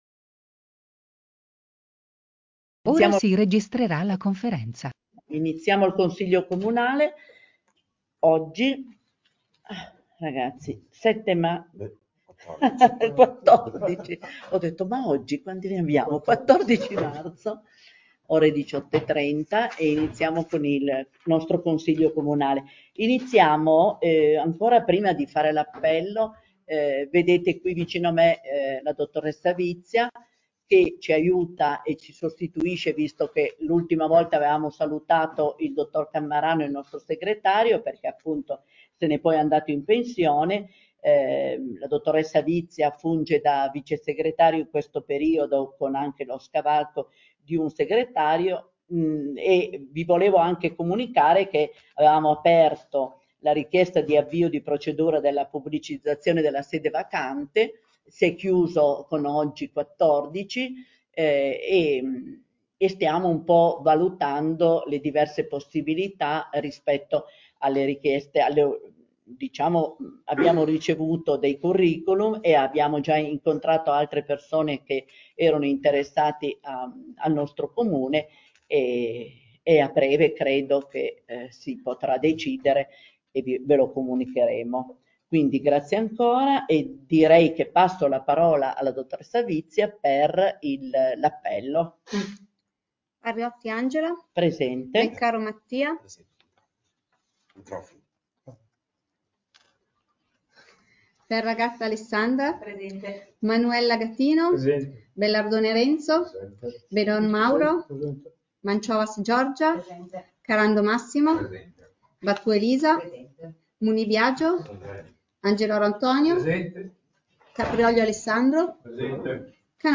Comune di Santhià - Registrazioni audio Consiglio Comunale - Registrazione Seduta Consiglio Comunale 14/03/2025